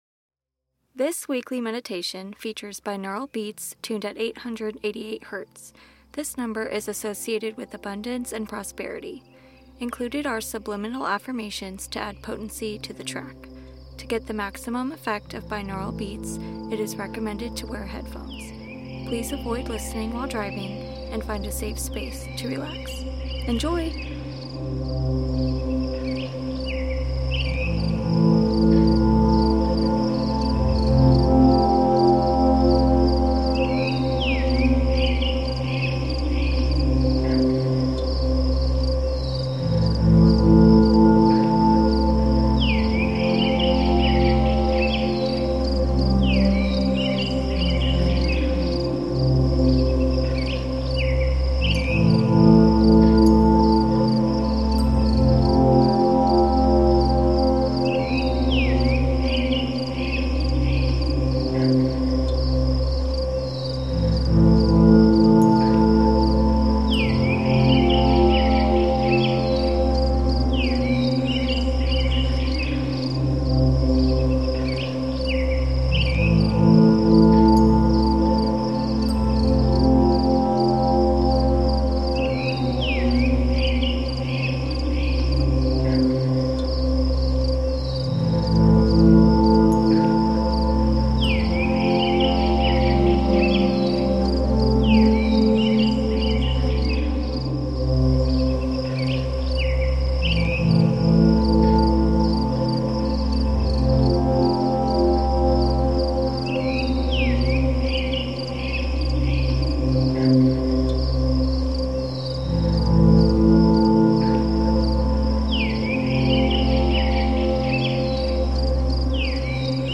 💚This weekly meditation features binaural beats tuned at 888 Hz. This number is associated with abundance and prosperity. Included are subliminal affirmations to add potency to the track. To get the maximum effect of binaural beats, it is recommended to wear headphones. However, feel free to enjoy this as meditation music or background sleeping music as well on any other devices.